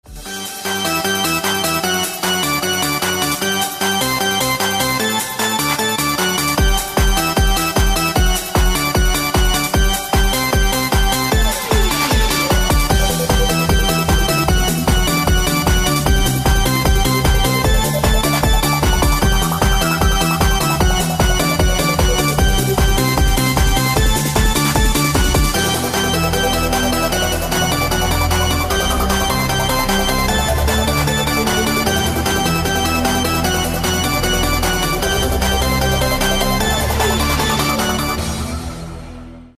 громкие
dance
Electronic
EDM
без слов
Euro House